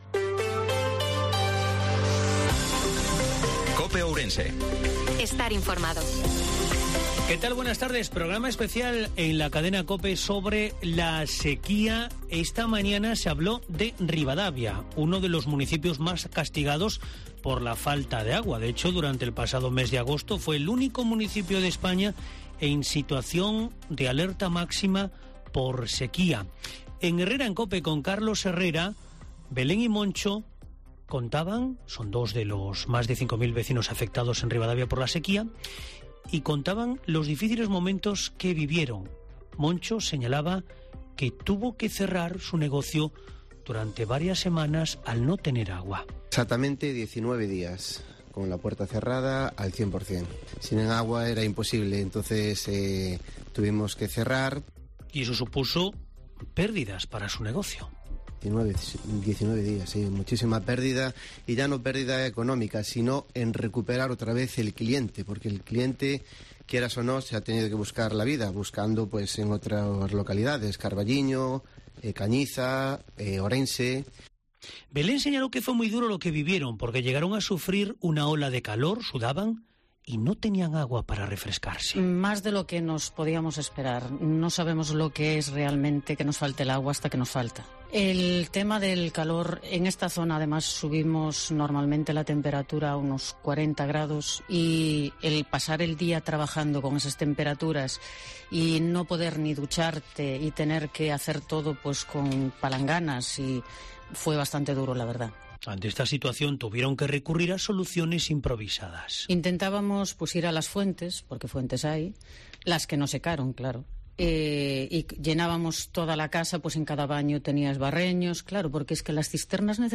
INFORMATIVO MEDIODIA COPE OURENSE-05/10/2022